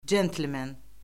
centlmen.mp3